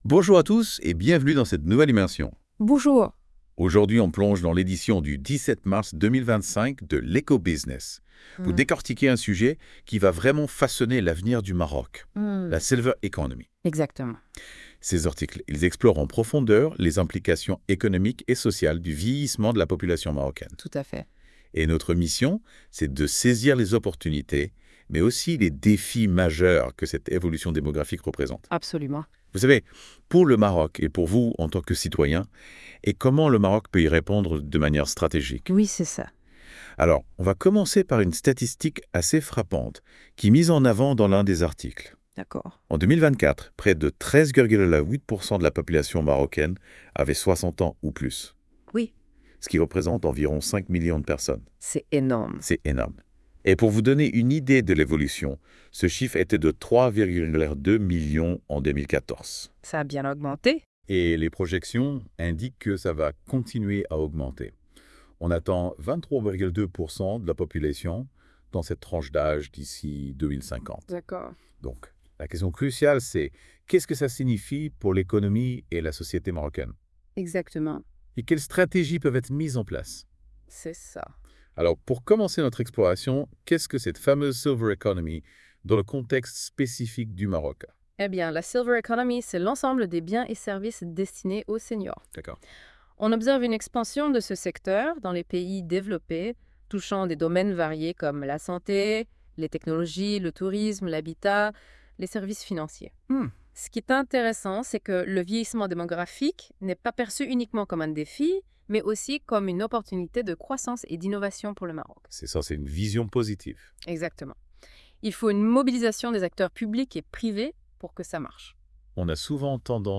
Débat (27.48 Mo) 1. Qu'est-ce que la Silver Économie et pourquoi est-elle considérée comme un levier stratégique pour l'avenir du Maroc ? 2. Quels sont les principaux défis du Maroc face au vieillissement de sa population en matière de santé et d'assistance aux seniors ? 3.